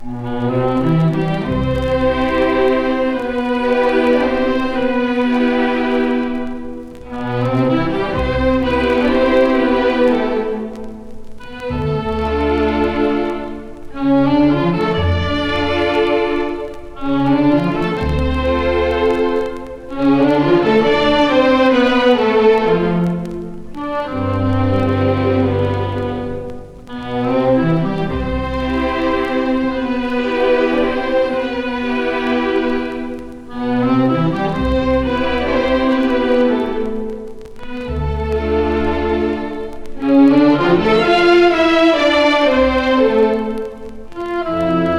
Jazz, Easy Listening, Lounge　USA　12inchレコード　33rpm　Stereo